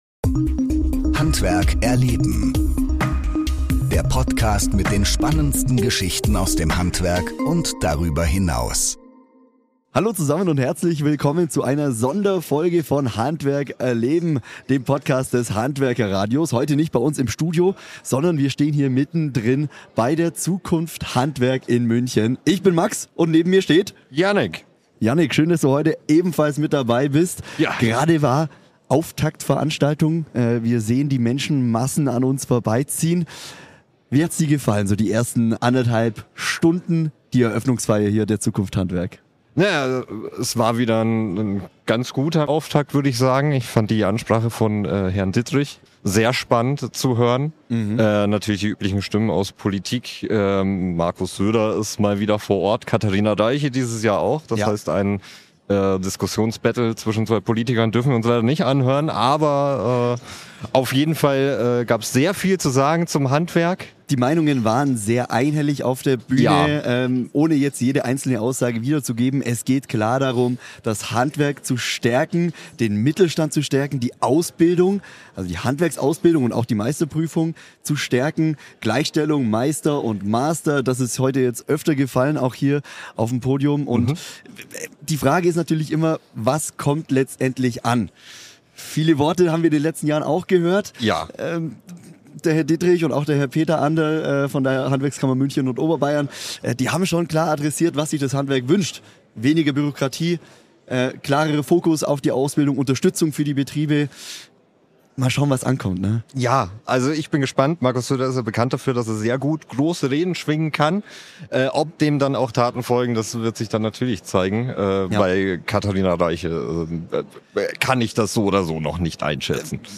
Mit dem rollenden Podcaststudio unterwegs über die ZUKUNFT HANDWERK 2026!
Direkt vor Ort sind spannende, interessante, unterhaltsame und emotionale Gespräche entstanden, die alle in diese Podcastfolge eingeflossen sind.